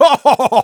Voice file from Team Fortress 2 German version.
Spy_laughshort06_de.wav